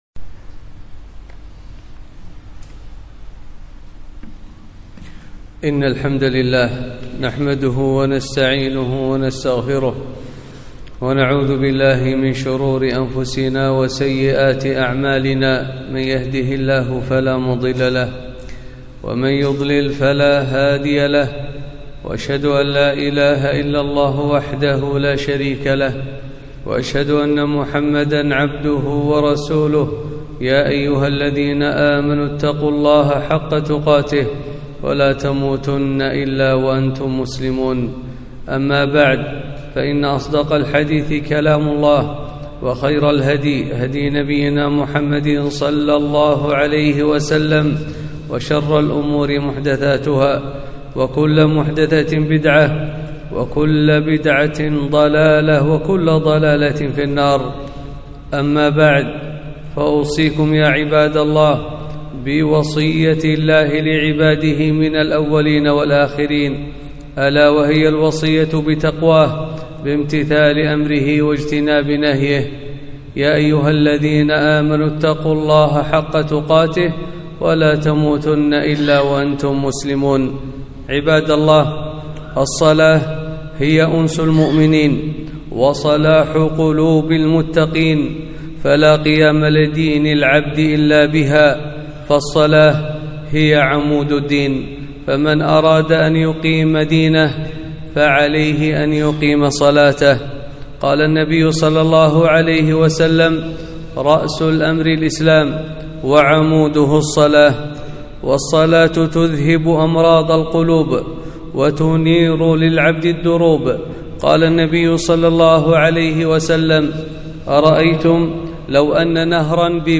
خطبة - الخشوع في الصلاة